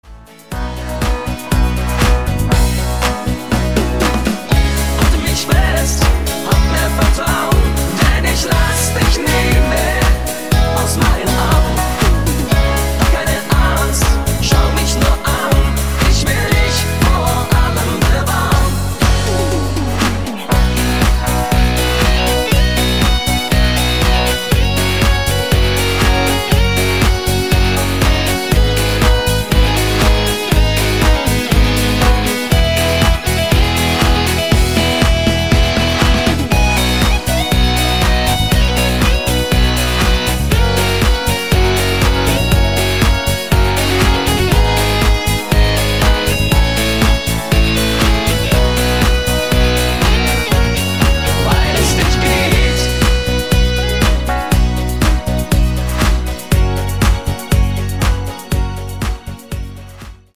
new Mix - Tanzversion